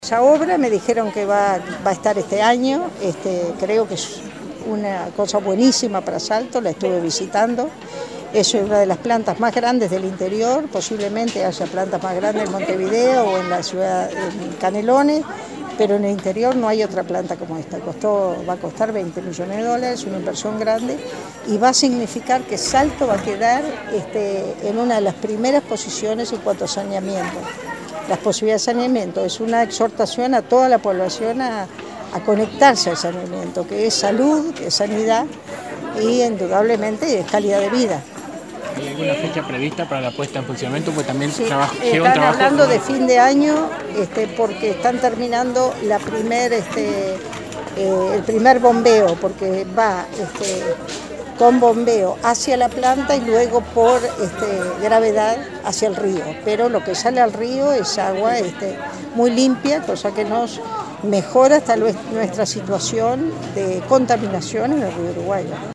La ministra de Vivienda, Ordenamiento Territorial y Medio Ambiente, Eneida de León explicó que esta obra demandó dos años de ejecución y una inversión cercana a los 20 millones de dólares, por parte de OSE.